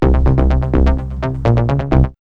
5808L B-LOOP.wav